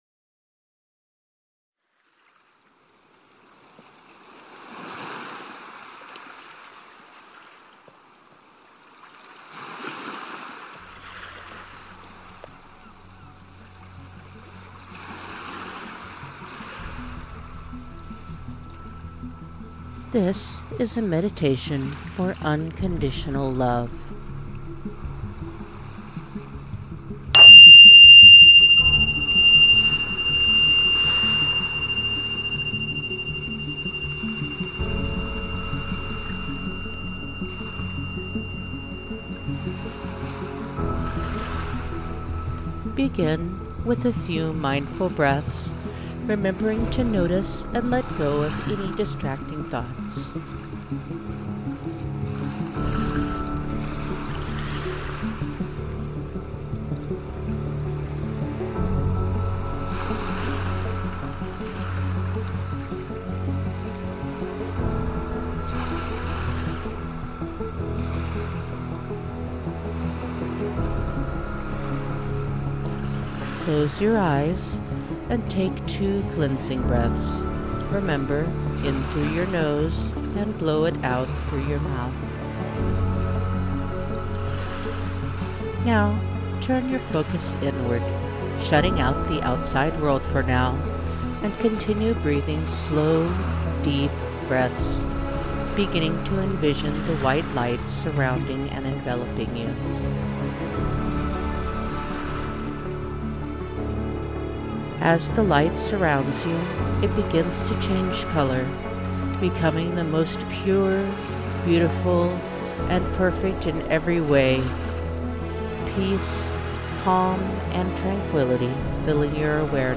GuidedMeditation-unconditionallove.mp3